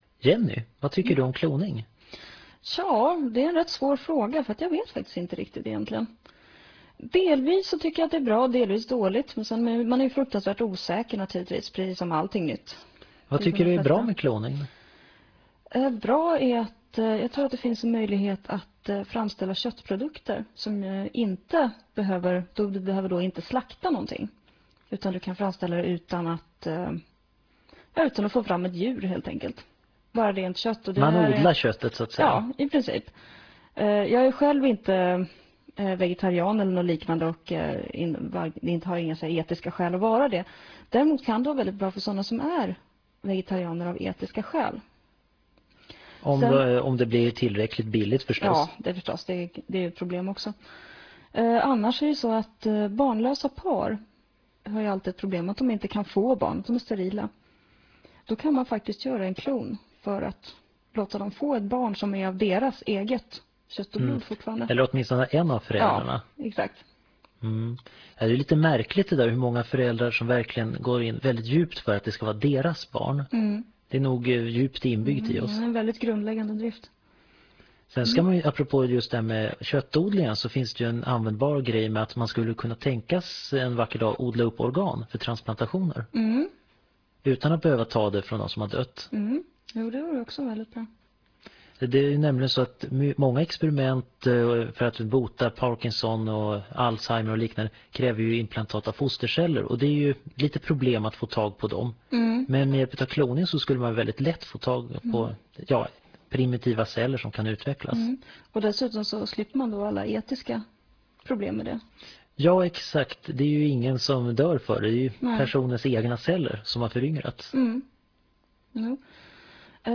Föredraget handlar om biologi och sänds februari 1998 i Etervåg.